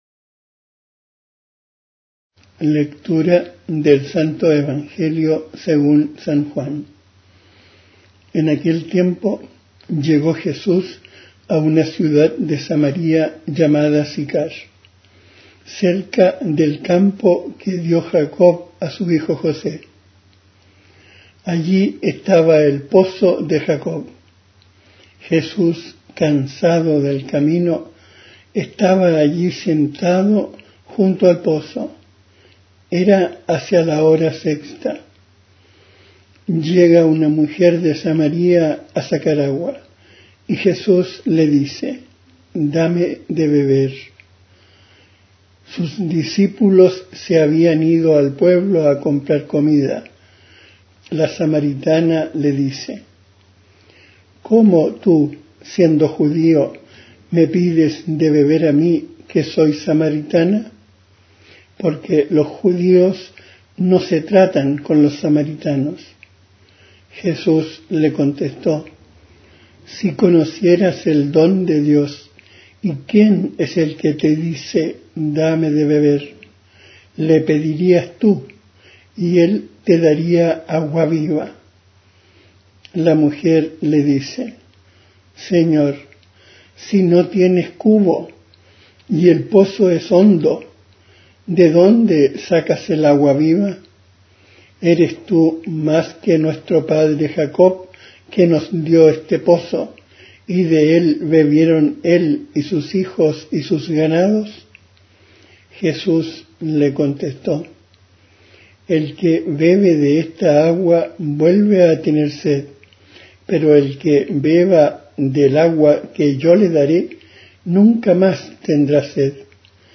Evangelio en audio.
Recita
Musica di sottofondo
J.S.Bach. Matthaeus Passion. Ermarme Dich mein Gott.